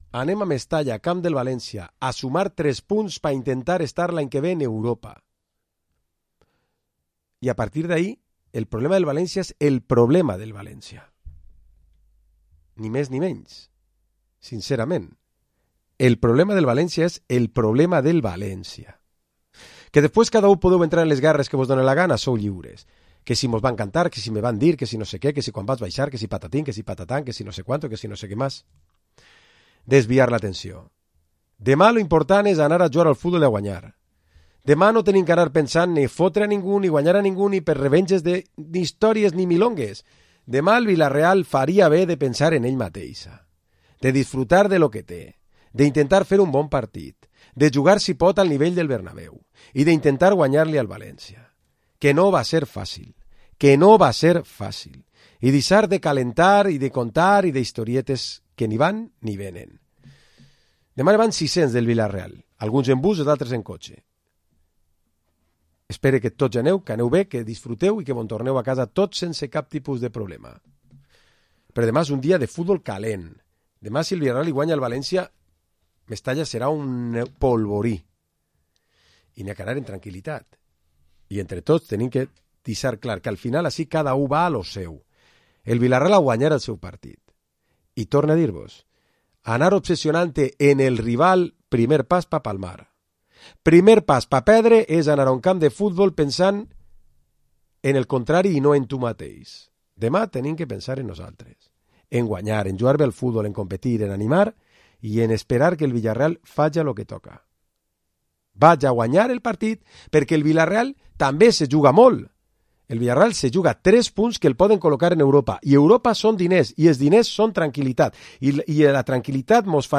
Programa esports dimarts tertúlia 2 de maig